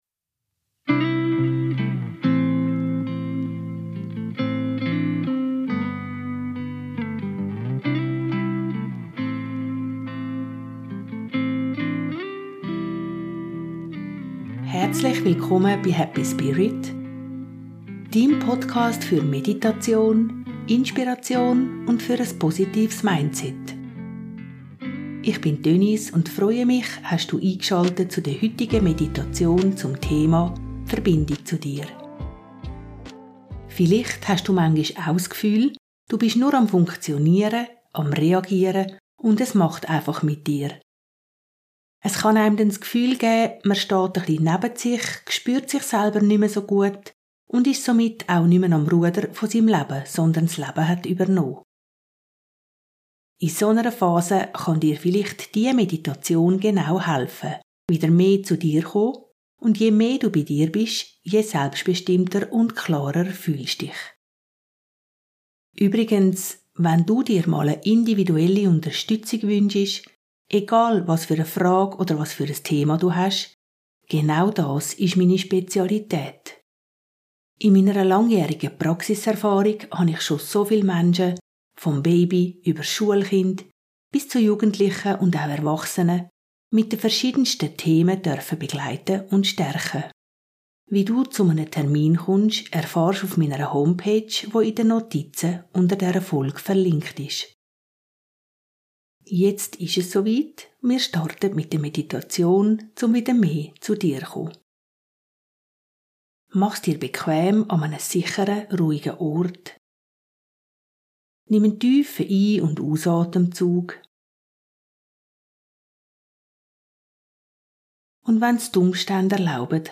#45 Meditation - Verbindung zu dir